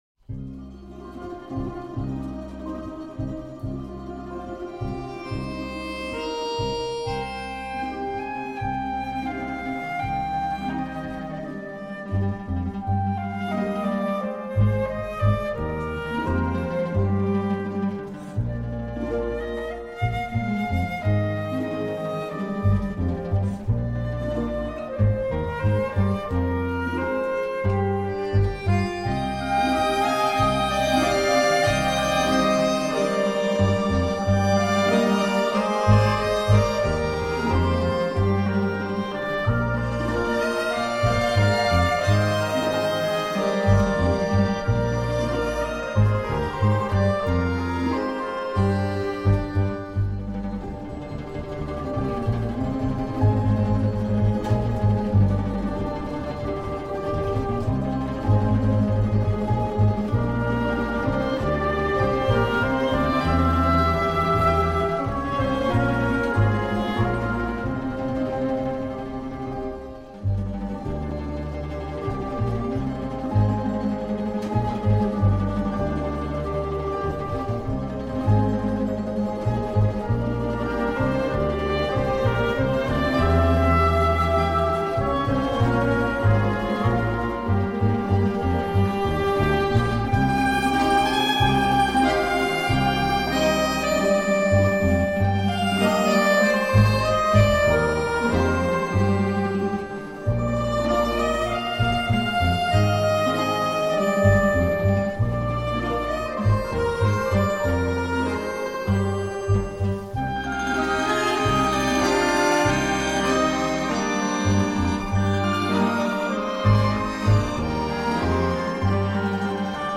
Произведение для народного оркестра. Используются темы народов Карачаево-Черкесии. Смысл — дружба и единство!